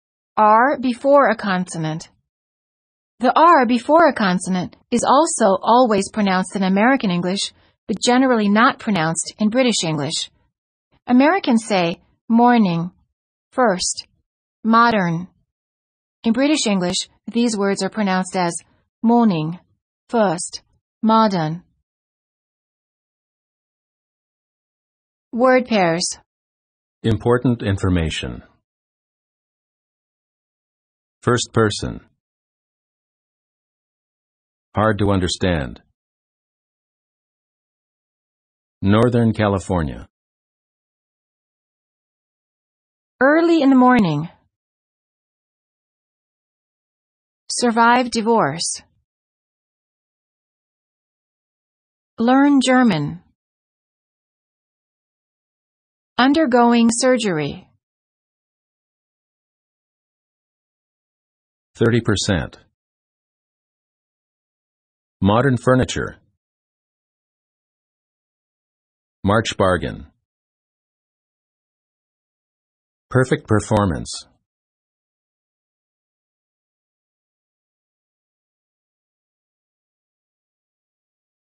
American Phonics Lesson 30b - American R